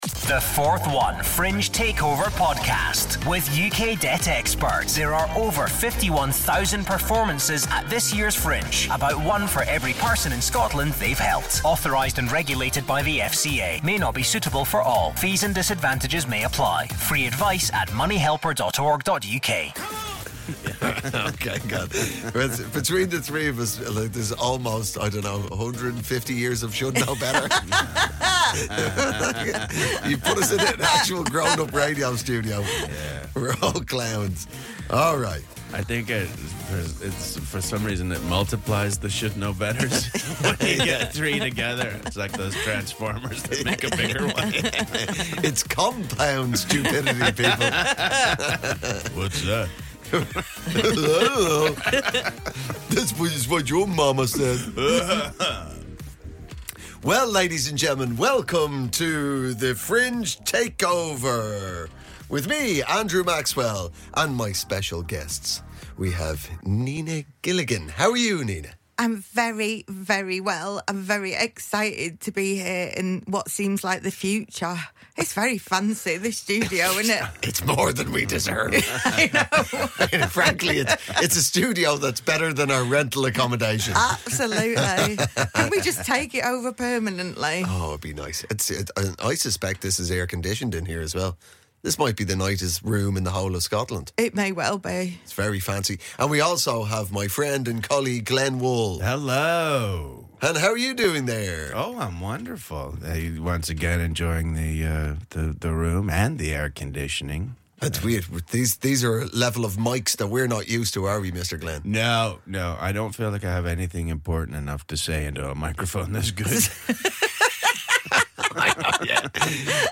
The Forth 1 Fringe Takeover 2024 is back for episode 3 with guest host Andrew Maxwell!